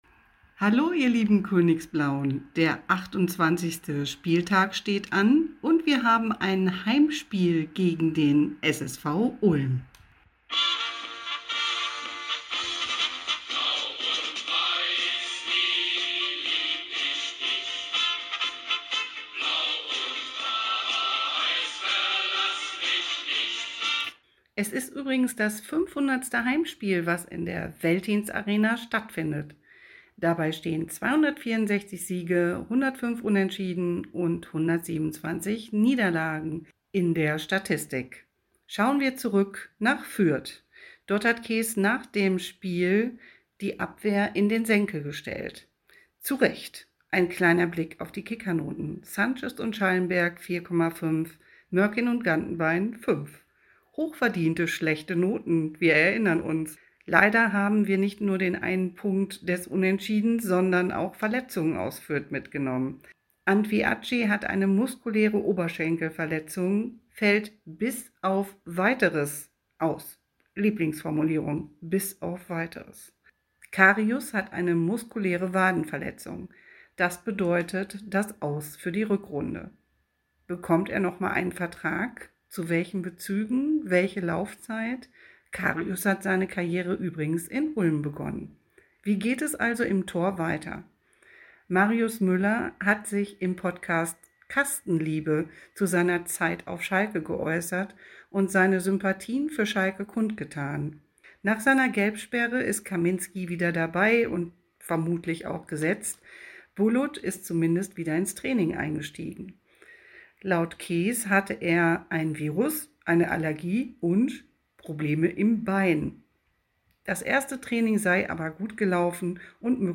Schalke Audio Re-Live